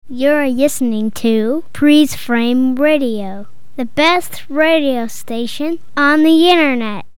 Bumper 1